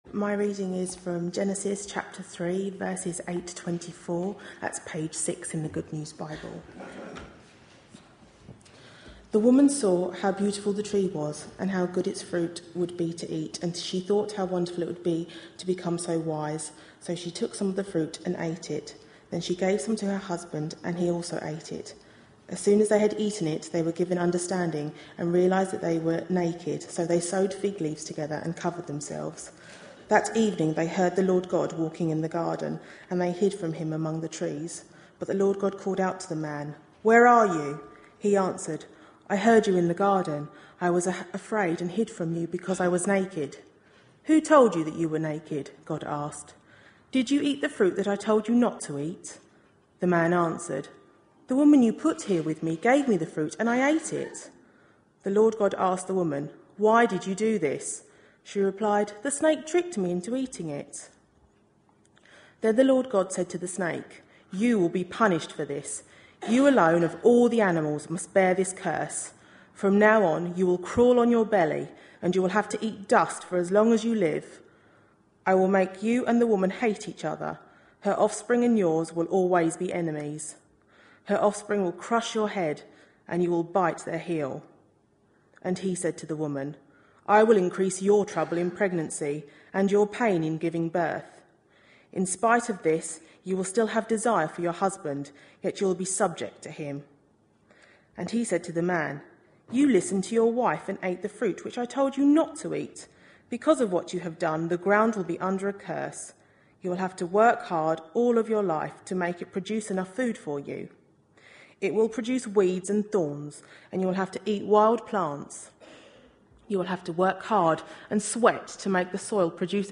A sermon preached on 6th May, 2012, as part of our Life in The Spirit series.